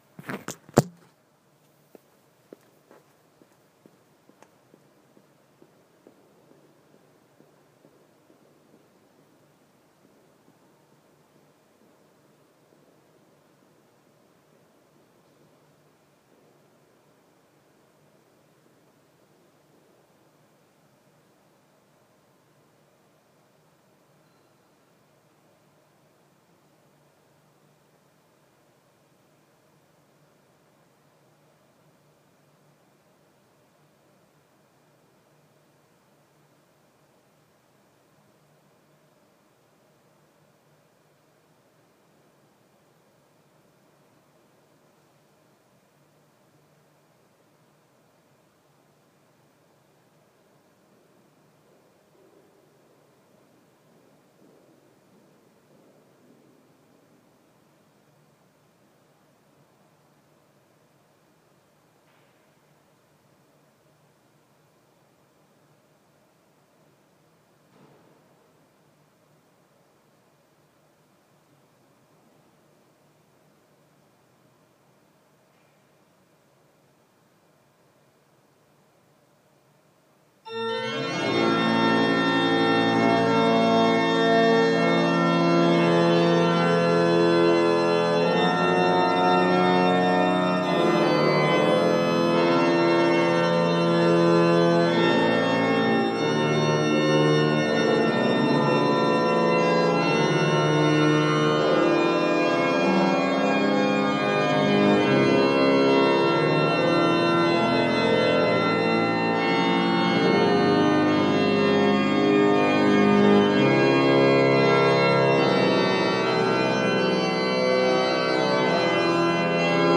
Orgelkonsert i Nidarosdomen, Trondheim
Orgelkonsert, orglar Nidarosdomen Wagnerorgelet, Wagnerorgeln, Steinmeyerorgeln, Steinmeyerorgelet